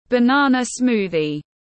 Sinh tố chuối tiếng anh gọi là banana smoothie, phiên âm tiếng anh đọc là /bəˈnɑː.nə ˈsmuː.ði/
Banana smoothie /bəˈnɑː.nə ˈsmuː.ði/